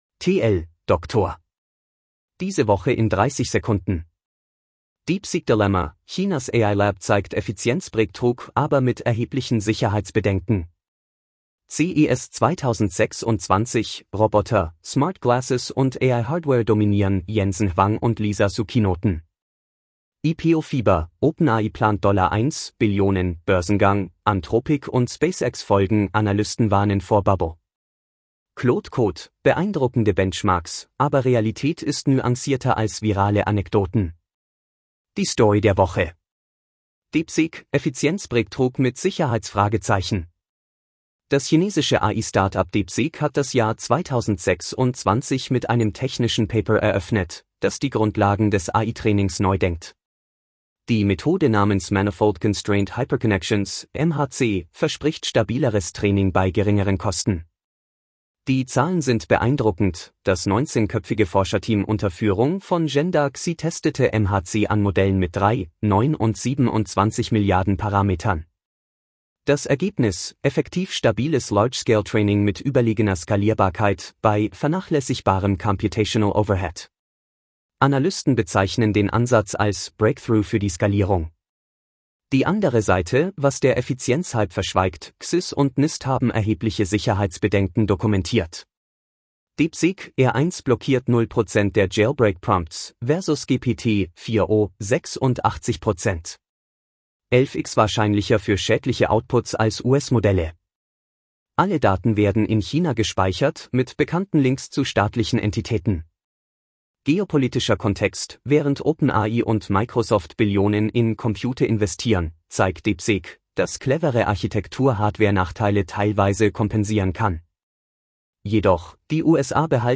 Vorgelesen mit edge-tts (Microsoft Azure Neural Voice: de-DE-ConradNeural)